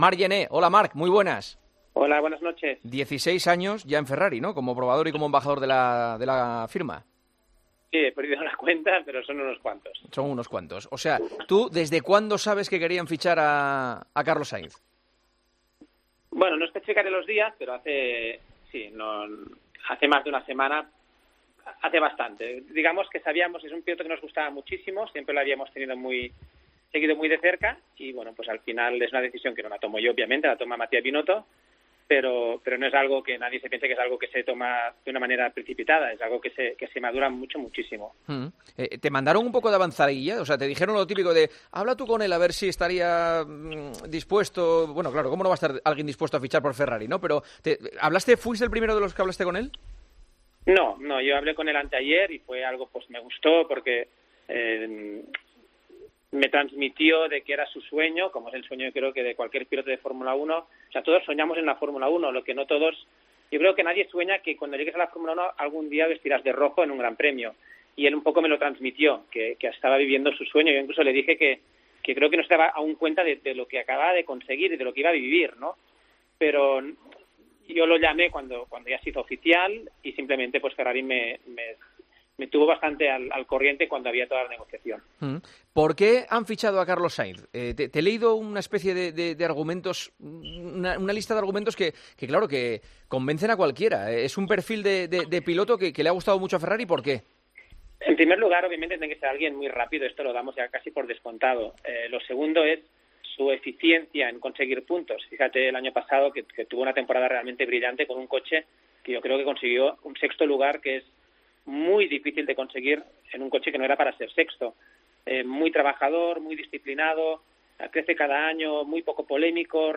AUDIO: El probador y embajador de Ferrari explica, en El Partidazo de COPE, los motivos del fichaje del pilioto español por la escudería italiana y cree que...